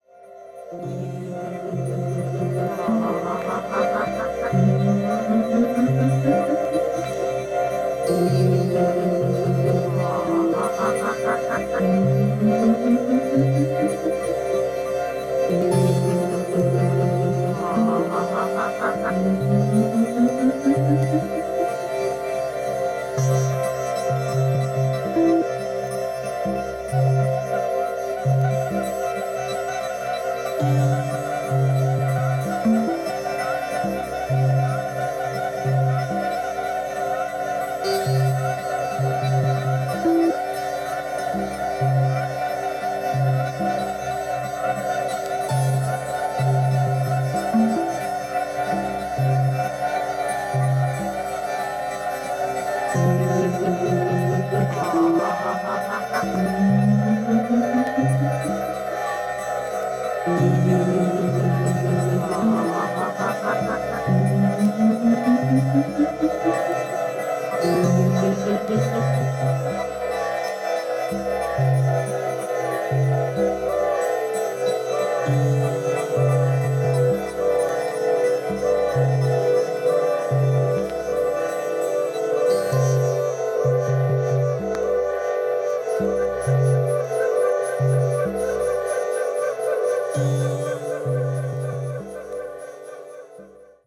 NY、イスラエルに住居を移し、2016年にサンフランシスコに戻って録音された傑作！！！